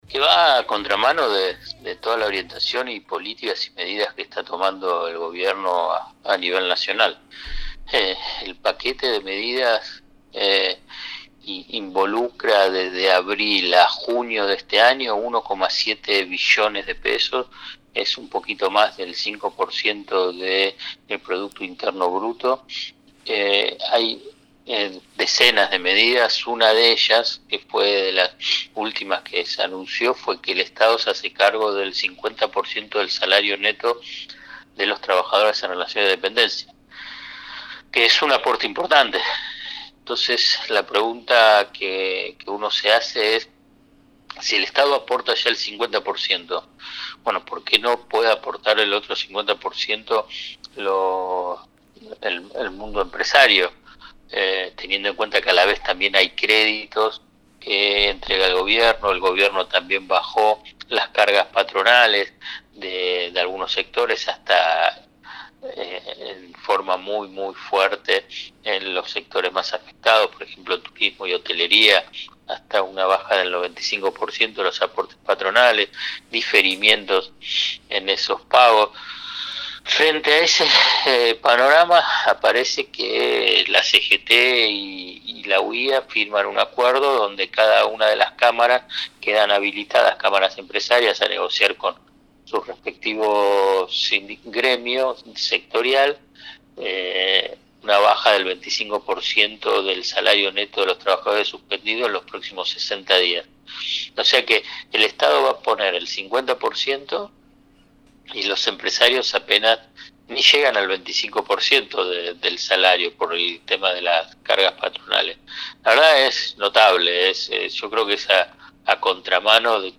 El economista, periodista y escritor Alfredo Zaiat, en diálogo con Proyecto Erre, se refirió al acuerdo entre la Confederación General del Trabajo y la Unión Industrial Argentina para recortar un cuarto del salario de trabajadoras y trabajadores suspendidos por el aislamiento social, con aval del Gobierno.
Alfredo-Zaiat-3004.mp3